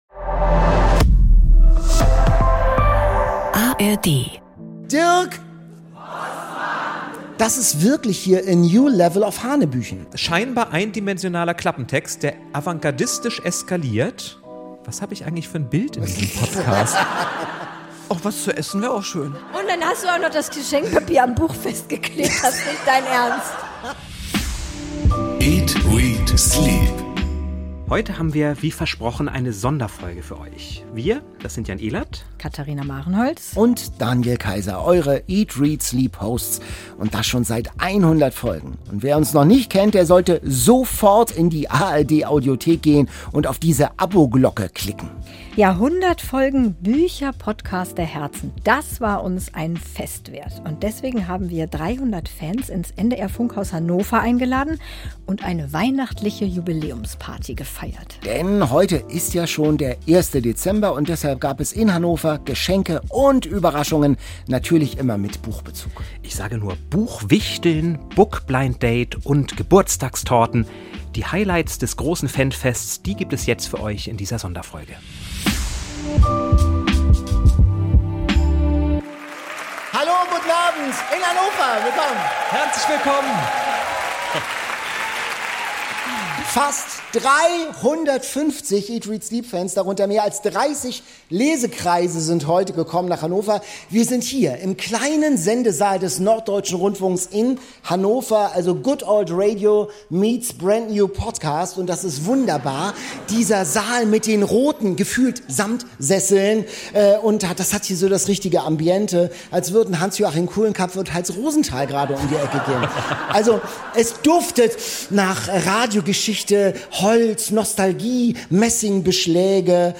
Die Highlights der Bühnenshow hört ihr in dieser Folge.